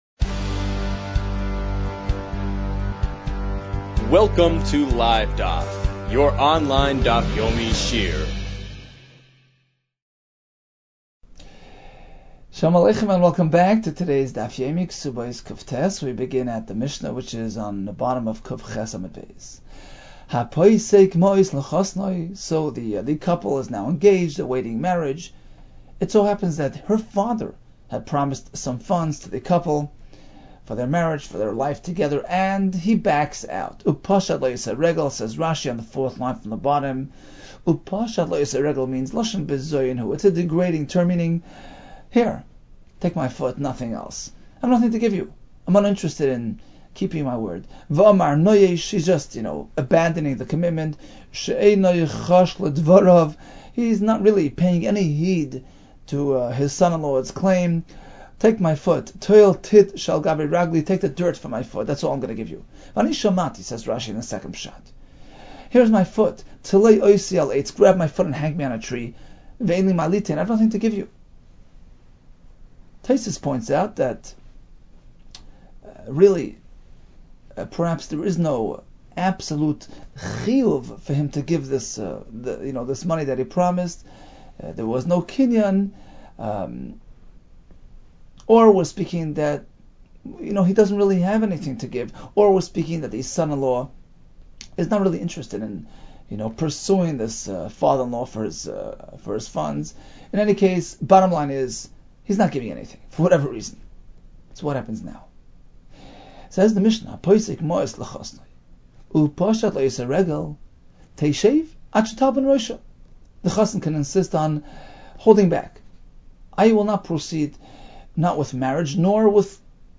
Kesubos 109 - כתובות קט | Daf Yomi Online Shiur | Livedaf